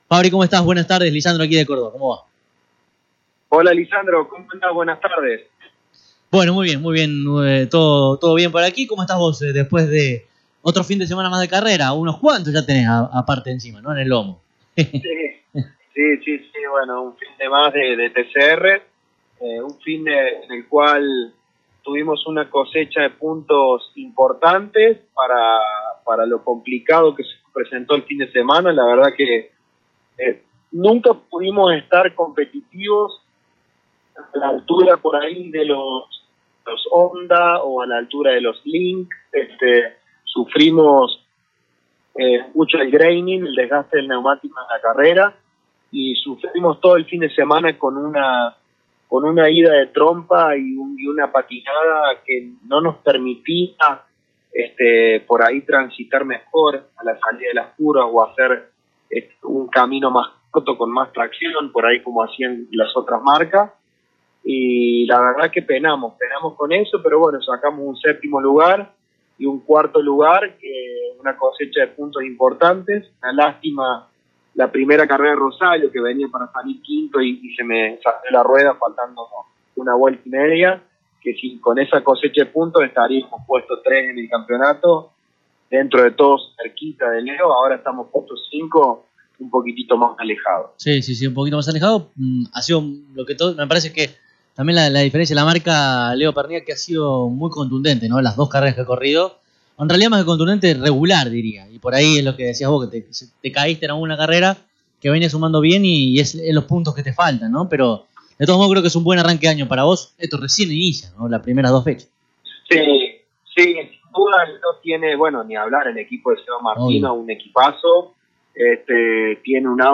Escuchá esta interesante y emotiva entrevista aquí debajo: